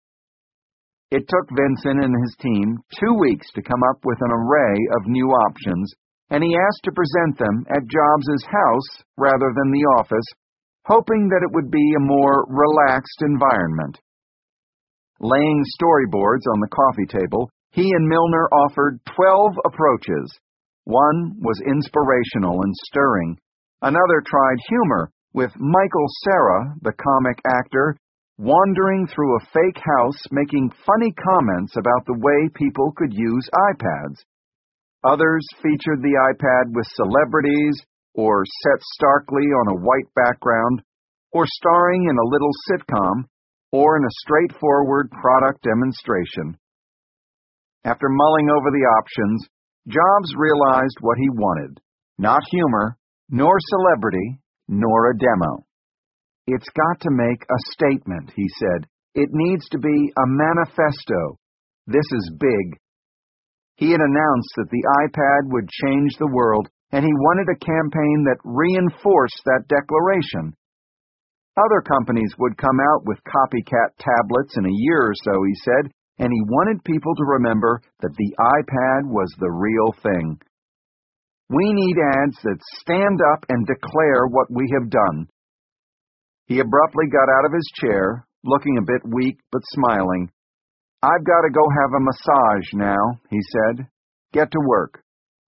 在线英语听力室乔布斯传 第693期:广告(3)的听力文件下载,《乔布斯传》双语有声读物栏目，通过英语音频MP3和中英双语字幕，来帮助英语学习者提高英语听说能力。
本栏目纯正的英语发音，以及完整的传记内容，详细描述了乔布斯的一生，是学习英语的必备材料。